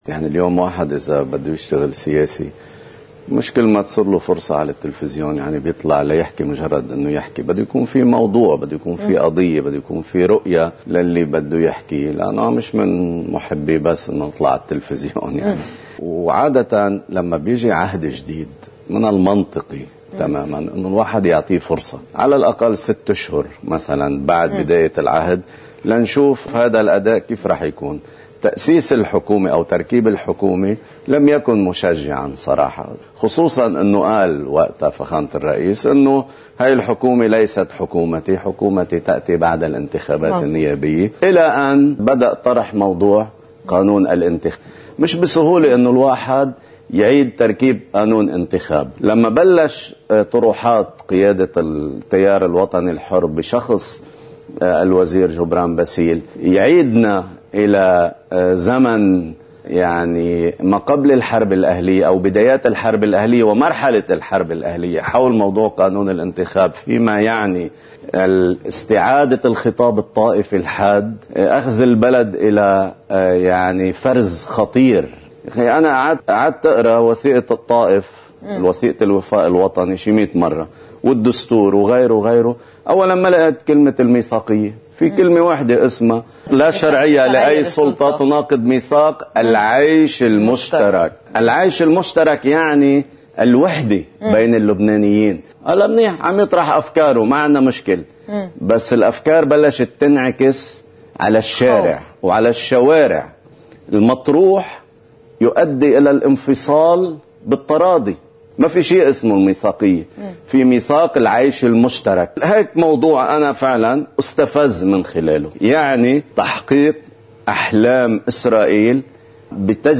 مقتطف من حديث
تتدخل الإعلامية